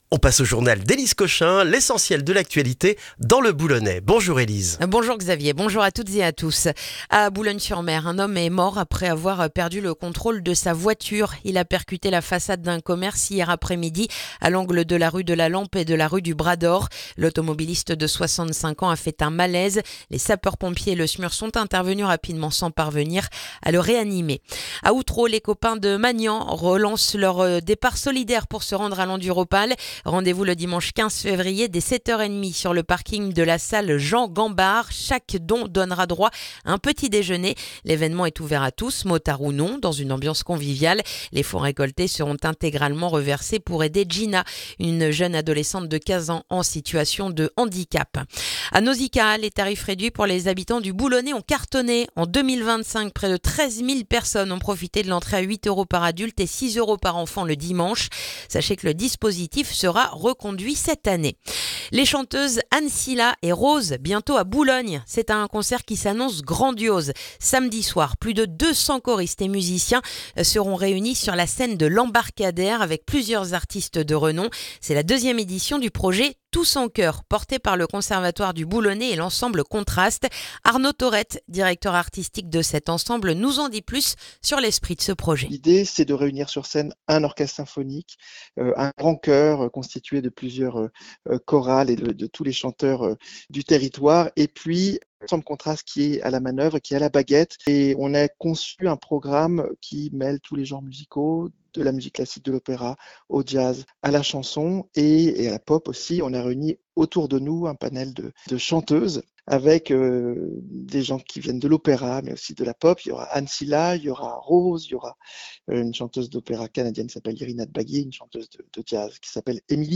Le journal du mercredi du 4 février dans le boulonnais